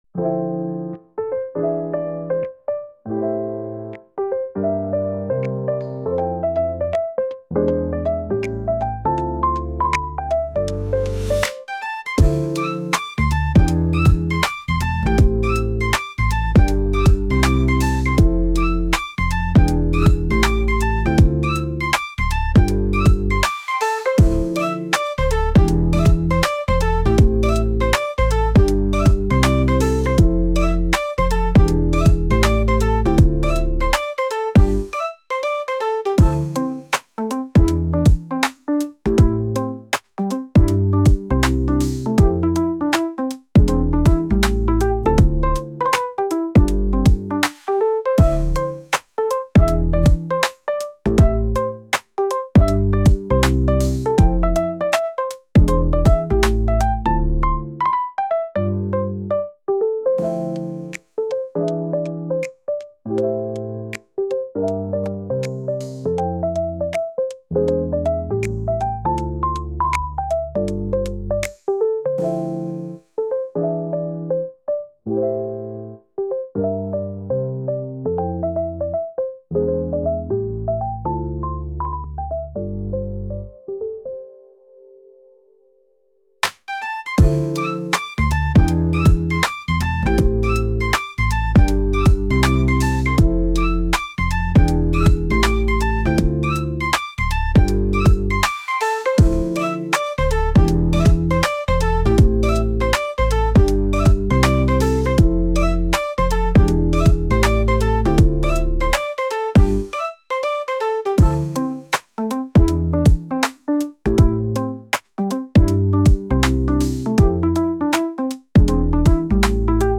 エレクトロ
Chill , Lo-Fi , ゆったり